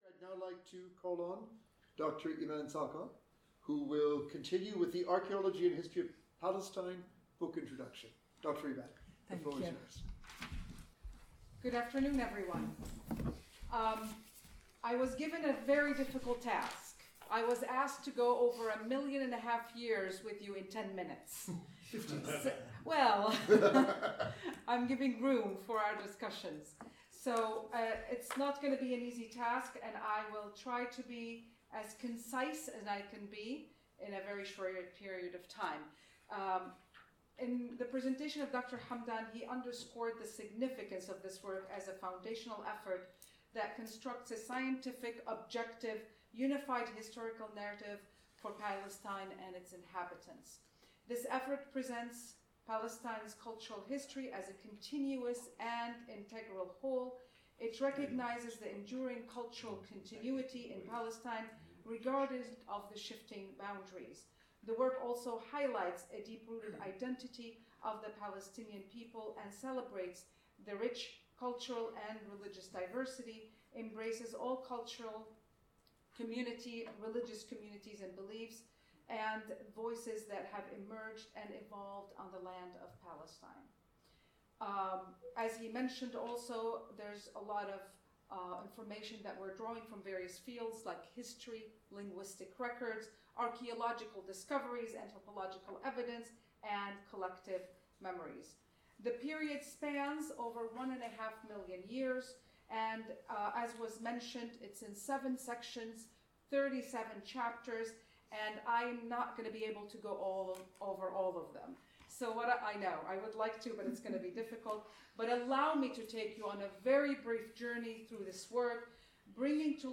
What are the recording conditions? The symposium was entitled ‘Toward an Inclusive Archaeological and Historical Narrative of Palestine: The Archaeology and History of Palestine’.